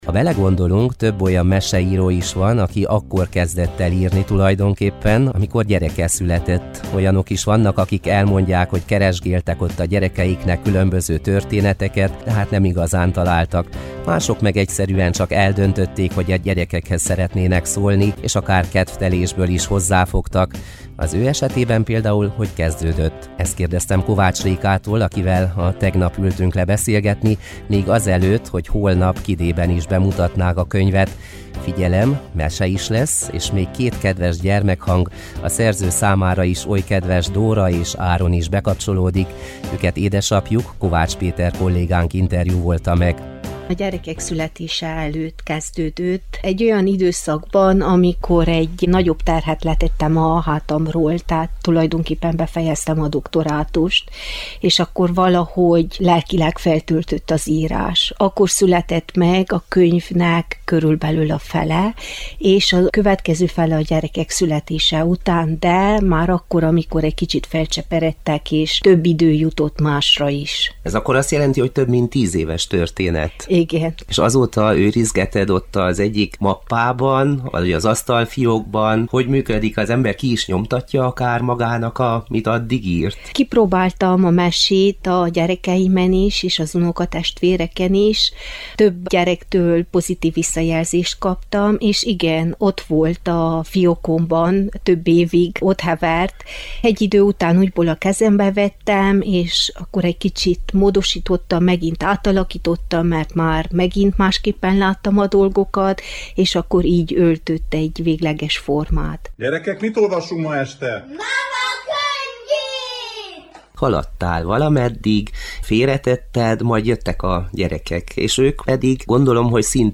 A szerzővel beszélgettünk.